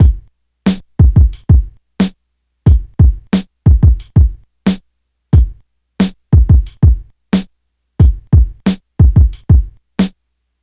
• 90 Bpm Drum Loop Sample B Key.wav
Free breakbeat - kick tuned to the B note. Loudest frequency: 527Hz
90-bpm-drum-loop-sample-b-key-3IZ.wav